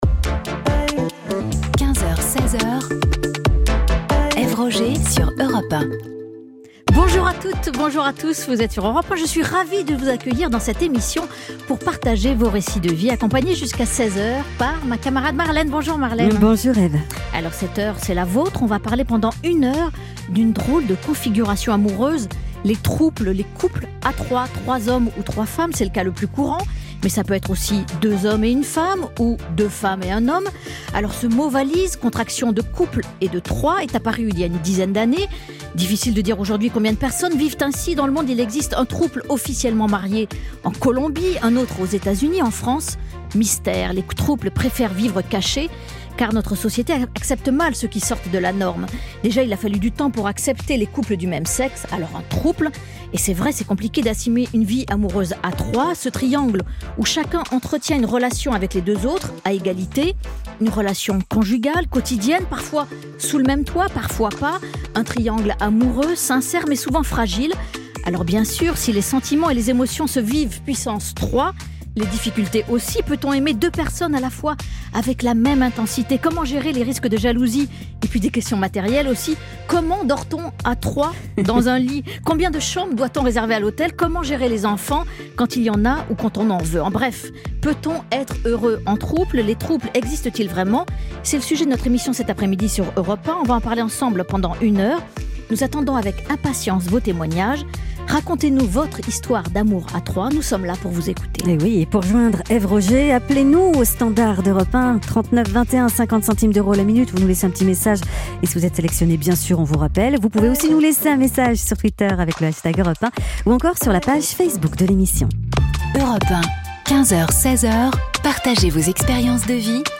Invitée de Brigitte Lahaie sur Sud Radio le 10 juillet 2019